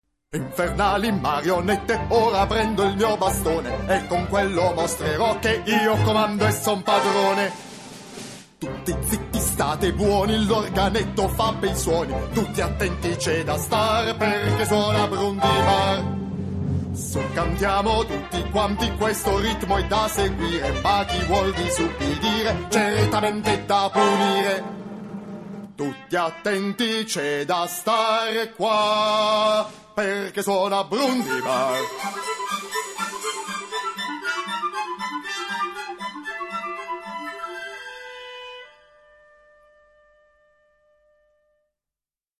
Si spegne così l’eco dell’ultimo accordo, tace la melodia di Krása, che tanto abilmente mescola temi popolari e musica del ‘900, ora in tenero abbandono lirico, ora nello squillare chiaro degli ottoni incalzato dal rullare ritmico delle percussioni.